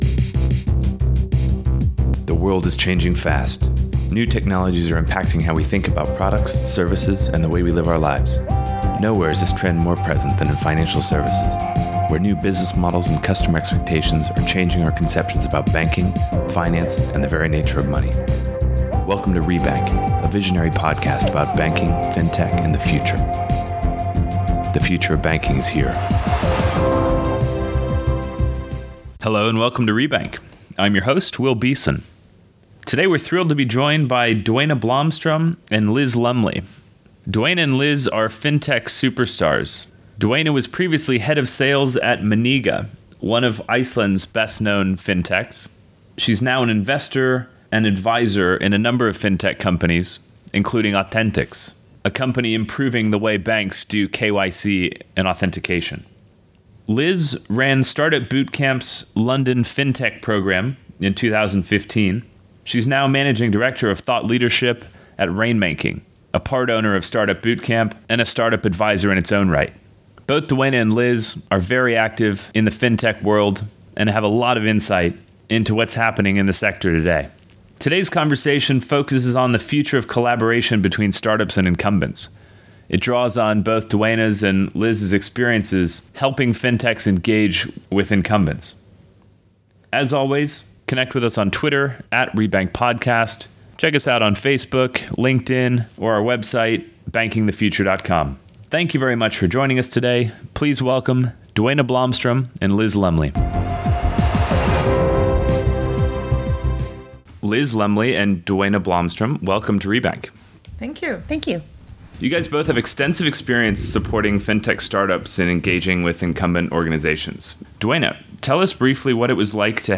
for an insightful conversation about bank-fintech collaboration